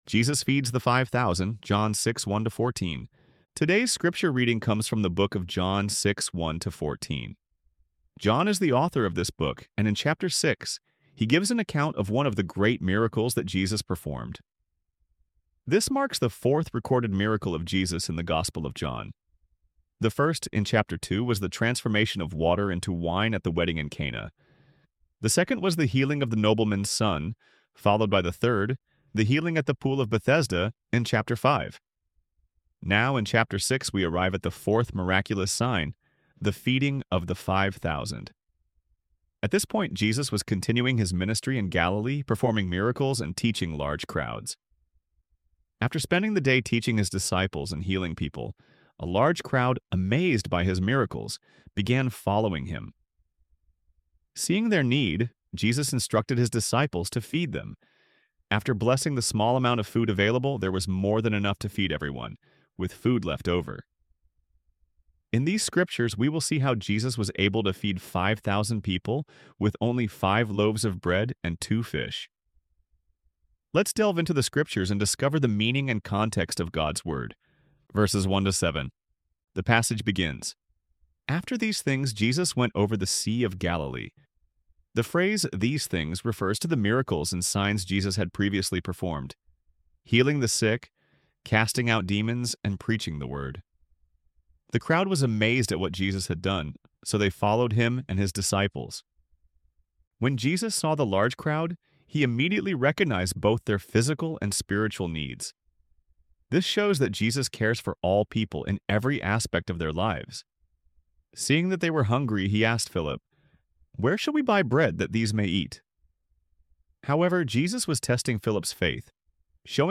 ElevenLabs_Bible_Study_on_Isaiah_55_1-7.-2.mp3